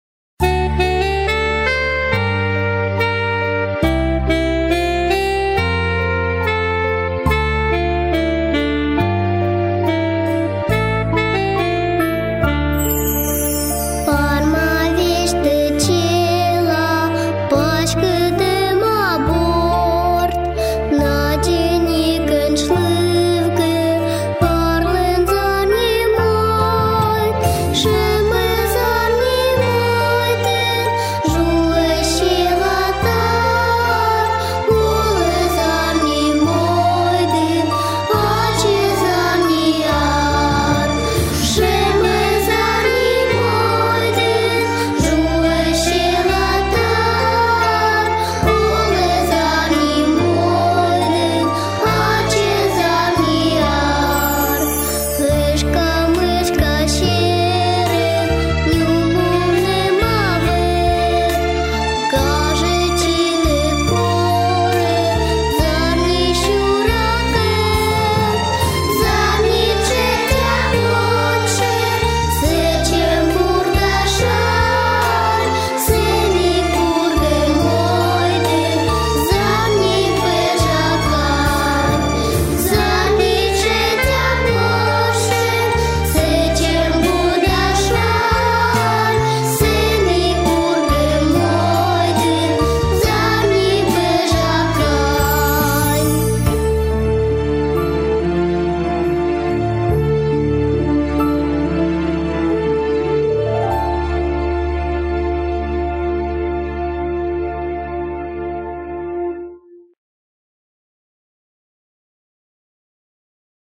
Сьыланкыв